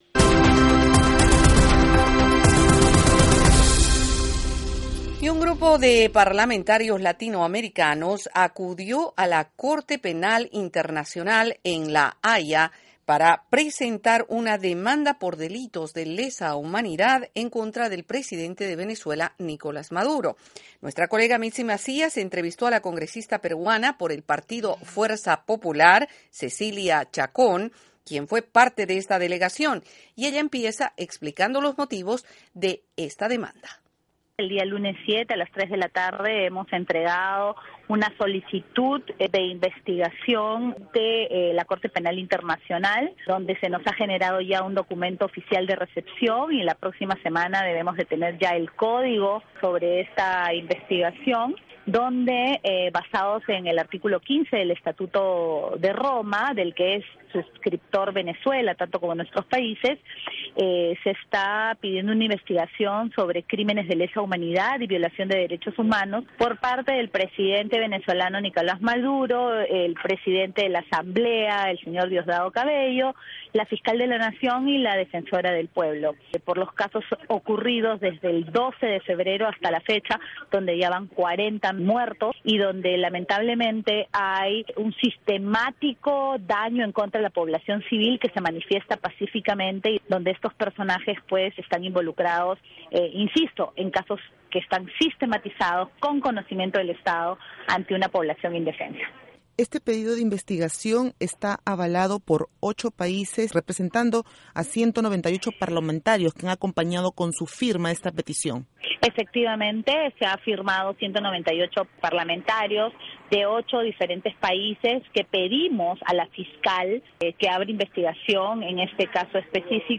Entrevista Congresista Cecilia Chacón